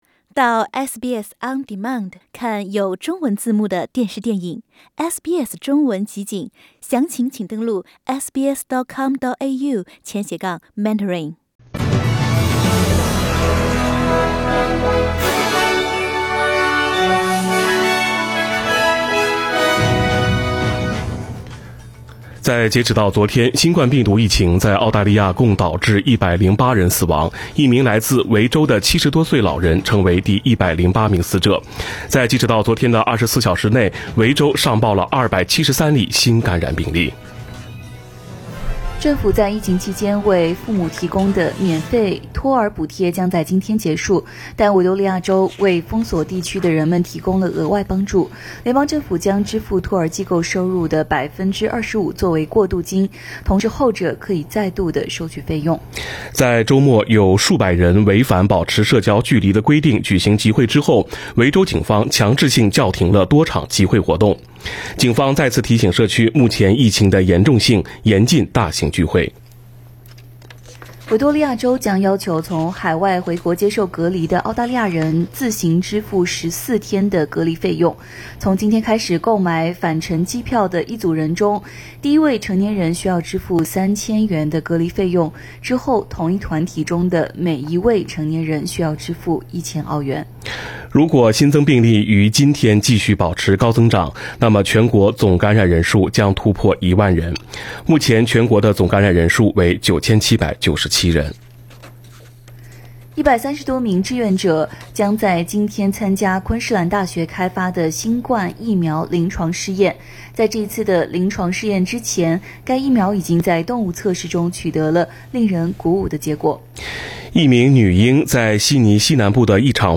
SBS早新闻 （7月13日）
SBS Chinese Morning News Source: Shutterstock